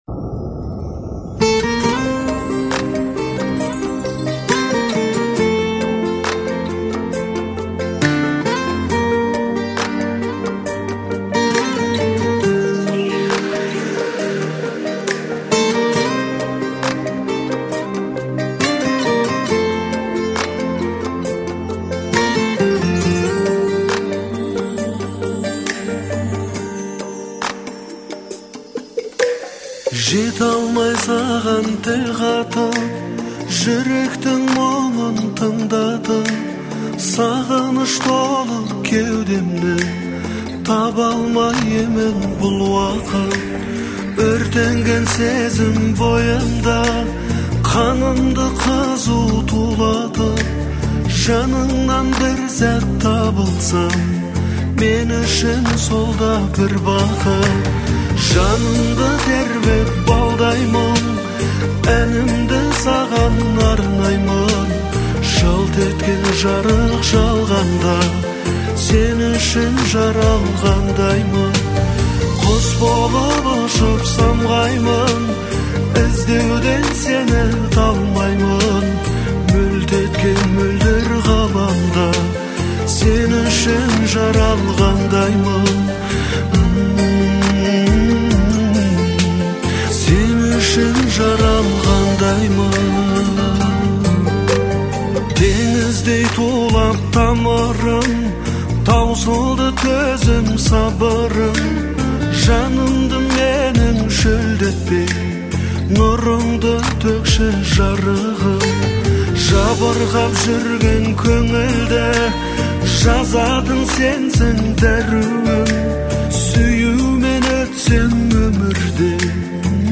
это проникновенная песня в стиле казахской поп-музыки